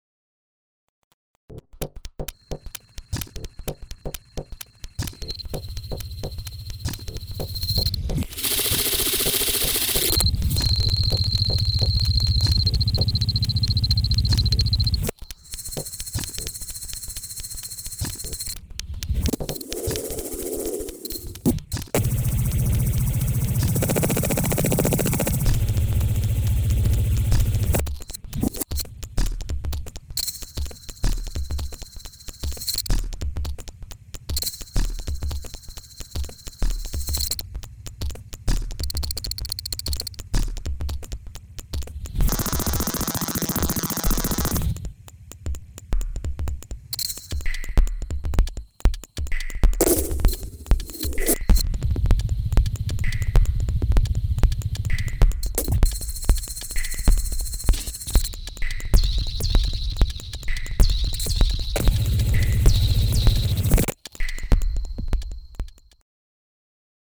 elektronische Musik